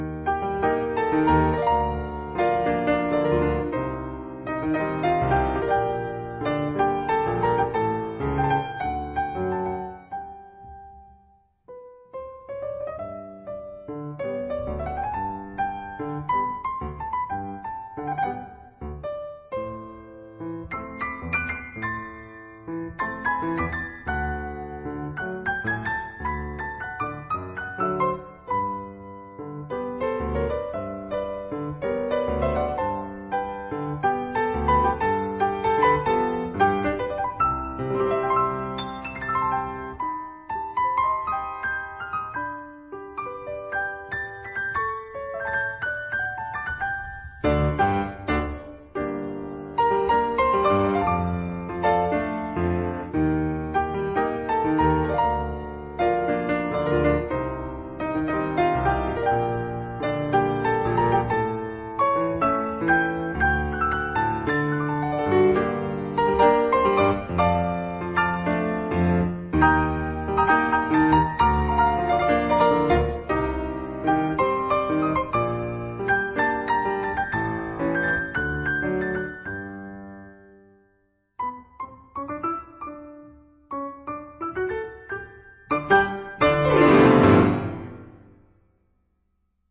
這回彈的是很接近Tango的Milonga。
實際上卻是更粗獷的Tango。
所以，整張錄音裡，有著強烈的低音表現。